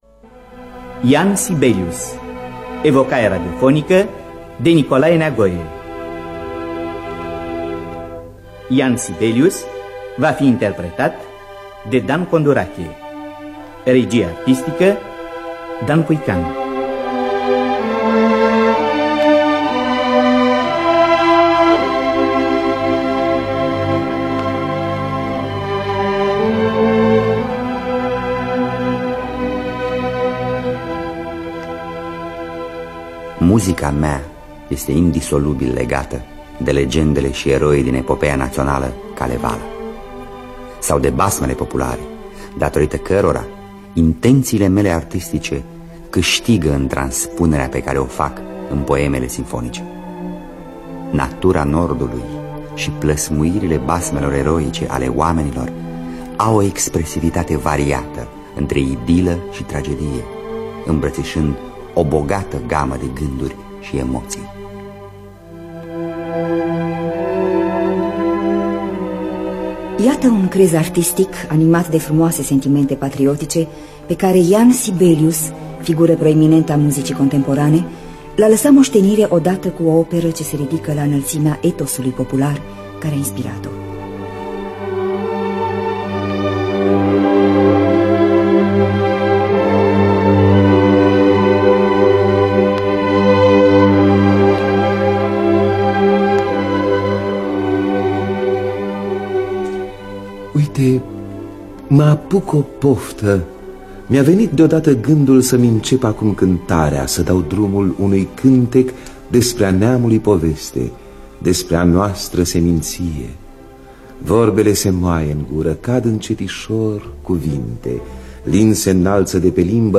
Scenariul radiofonic